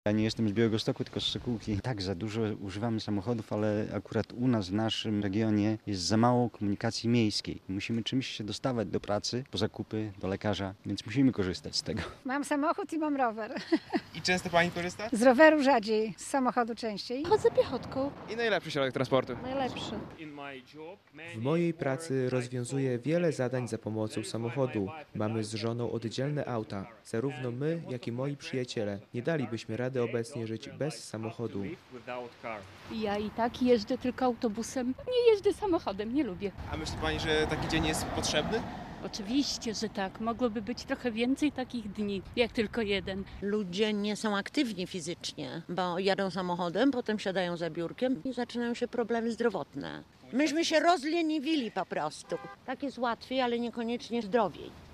Zapytaliśmy białostoczan, czy potrafią na co dzień obyć się bez auta.